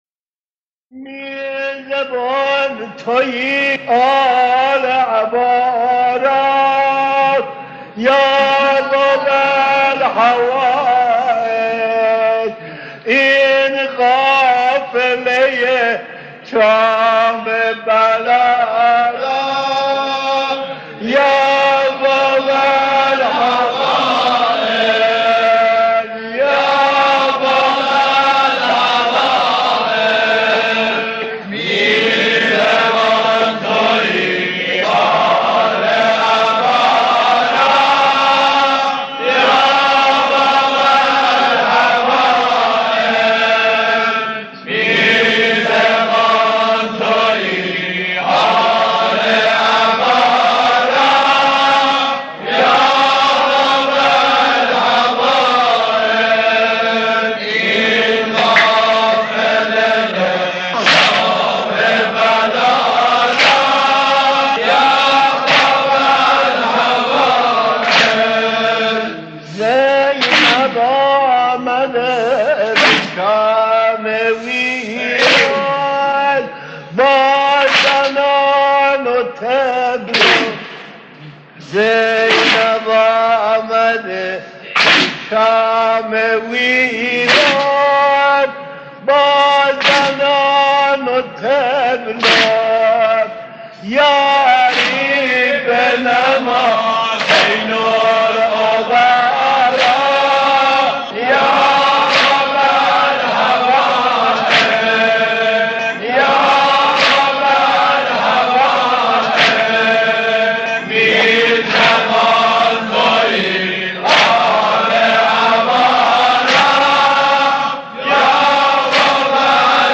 در پرده عشاق، صدای مداحان و مرثیه‌خوانان گذشته تهران قدیم را خواهید شنید که صدا و نفس‌شان شایسته ارتباط دادن مُحب و مَحبوب بوده است.
دم سینه زنی اربعین از زبان حضرت زینب (س) خطاب به حضرت عباس (ع)